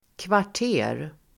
Uttal: [kvar_t'e:r]